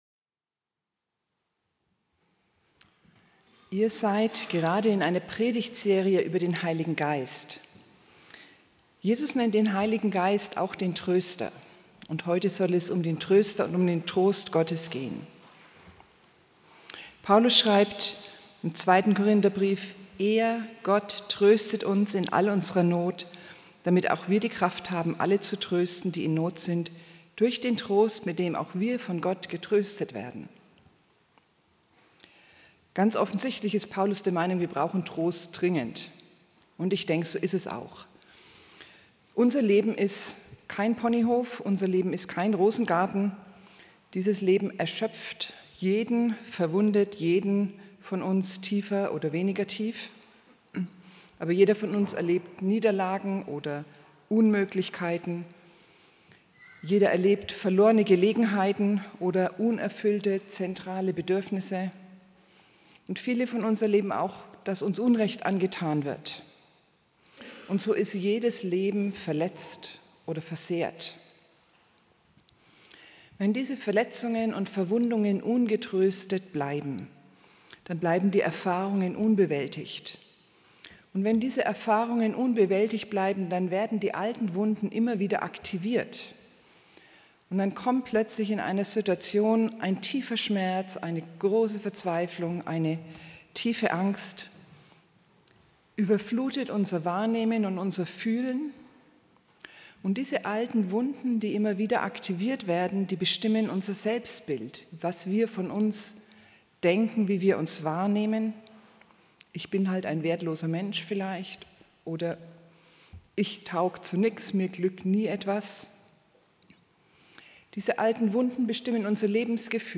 Teil 6 53.66 MB Podcast Podcaster eAg PredigtCast Predigten aus einANDERERGottesdienst Religion & Spiritualität Podcast aneignen Beschreibung vor 1 Monat Mehr Weitere Episoden Was ist Wahrheit?